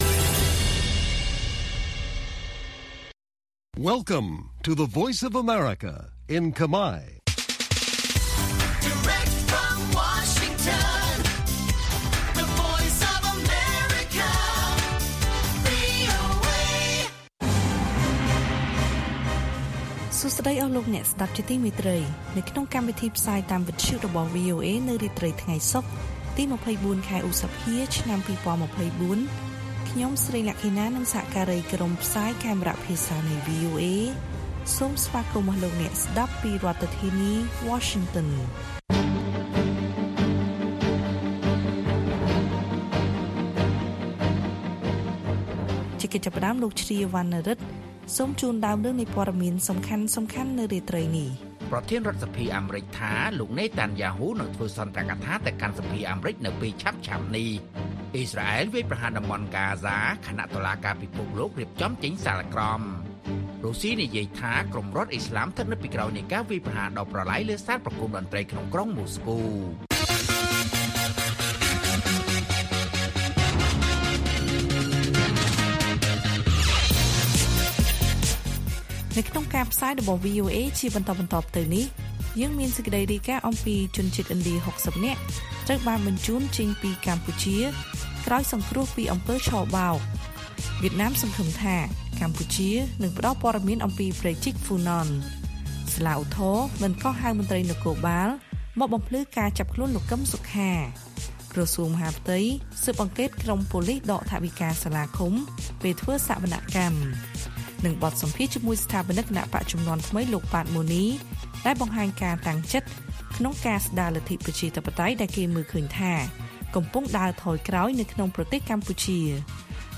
ព័ត៌មានពេលរាត្រី ២៤ ឧសភា៖ ជនជាតិឥណ្ឌា៦០នាក់ត្រូវបញ្ជូនចេញពីកម្ពុជា ក្រោយសង្រ្គោះពីអំពើឆបោក